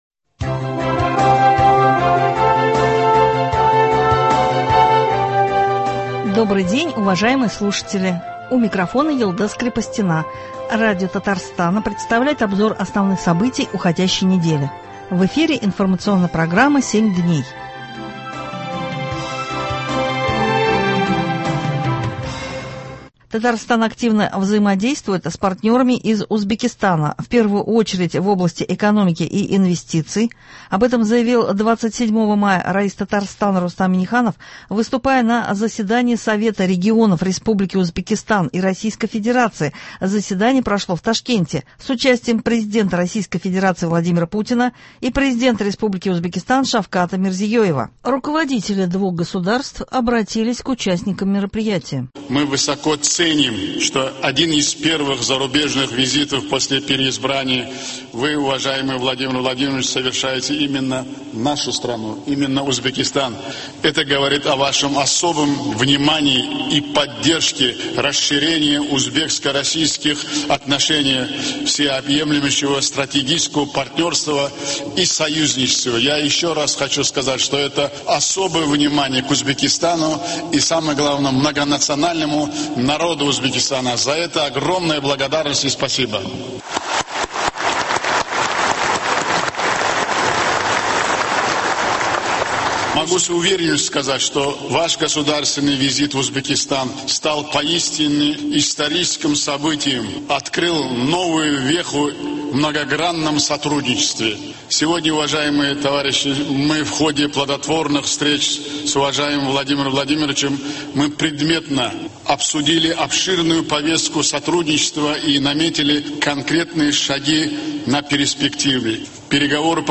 Обзор событий недели.